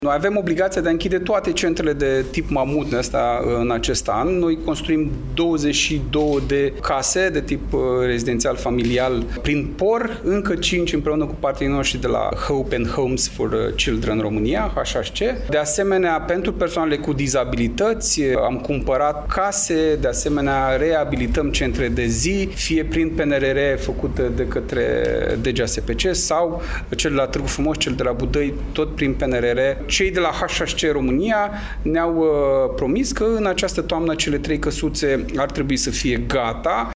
Astăzi, într-o conferință de presă, vicepreședinte al Consiliului Județean Iași, Marius Dangă, a arătat că planul de urgență și relocarea minorilor se face doar în cazul în care viața acestora este pusă în pericol.